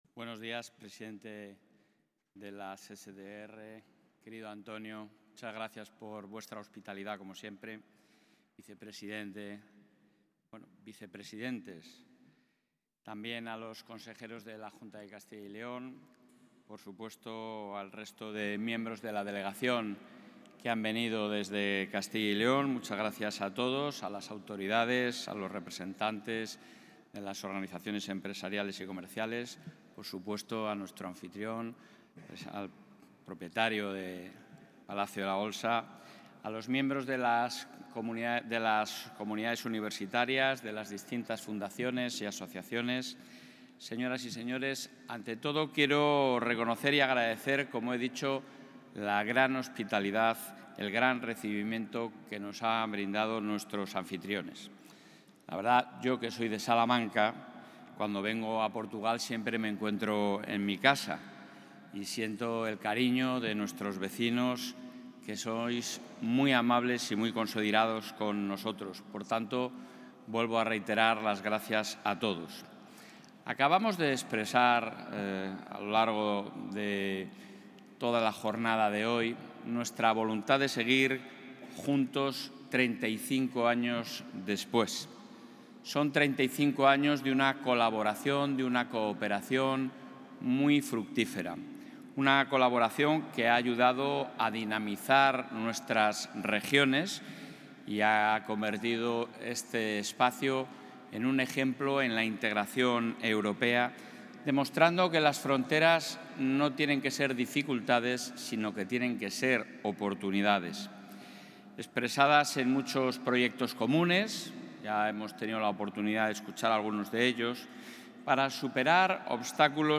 Intervención del presidente de la Junta.
El presidente de la Junta ha participado en la V Sesión Plenaria de la Comunidad de Trabajo Castilla y León – Región Norte de Portugal, donde se ha acordado actualizar el Convenio de Cooperación Transfronteriza para seguir colaborando en distintas áreas como sanidad, cultura, medioambiente, educación o conectividad, entre otras.